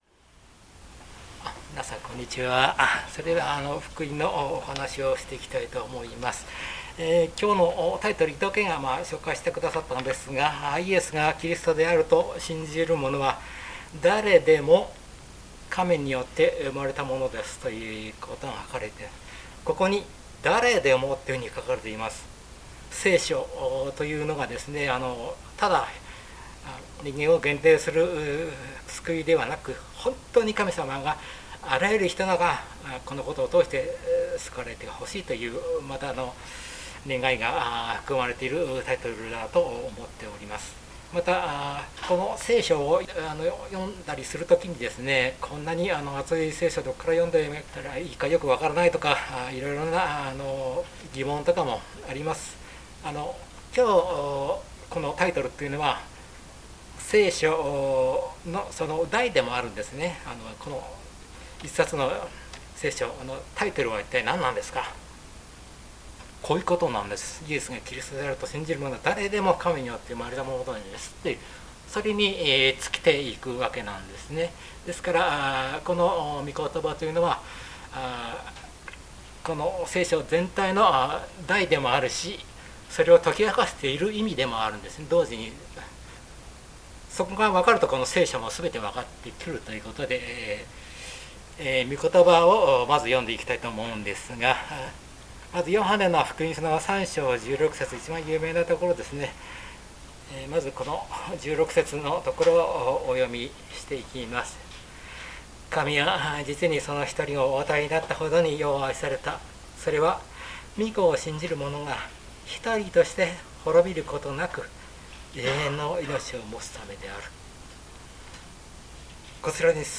聖書メッセージ No.170